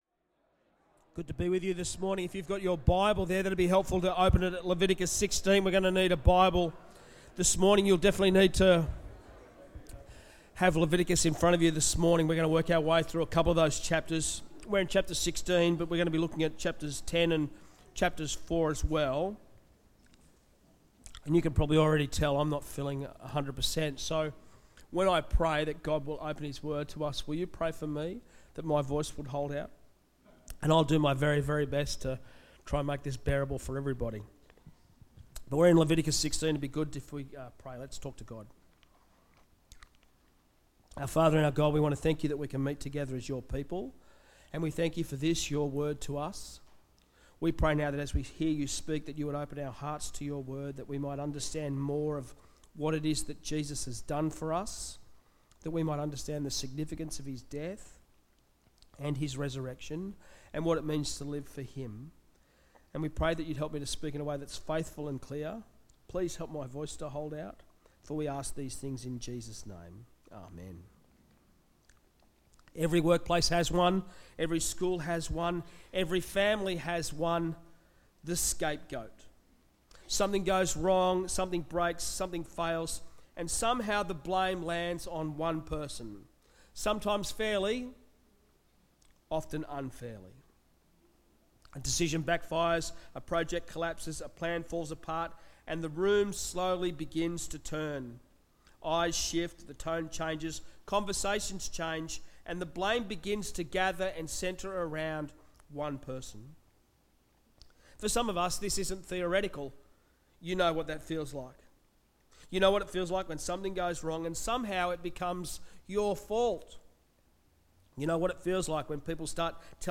Sermons
Listen to our sermons from Sunday here
Bible talk from Leviticus 16. The Day of Atonement confronts us with the reality of guilt and God’s provision for dealing with it.